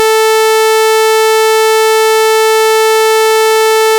Вот две пилы, слышно и в колонках, и в наушниках. Перевёрнутый файл выкупается как более высокий.